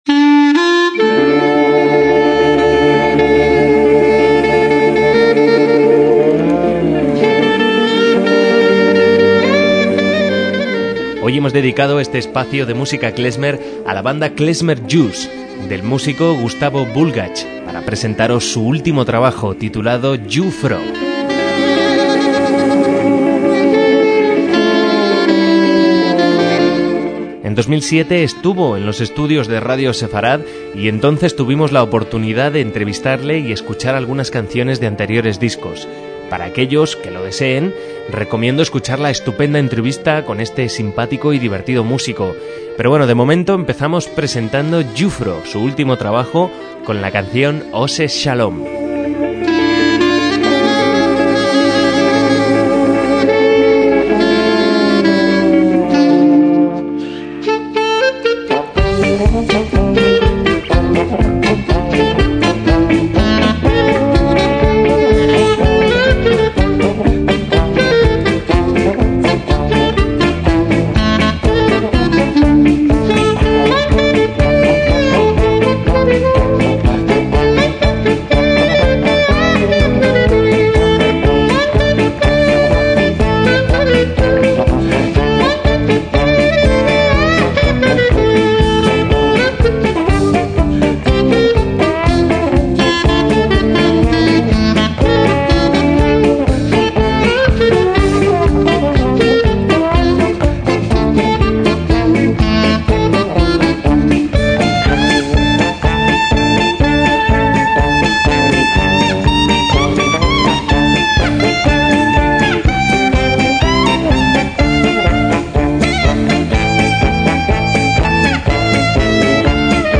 MÚSICA KLEZMER - La música klezmer, originaria de los judíos del este de Europa no sólo llegó a EE.UU. sino también a Sudamérica.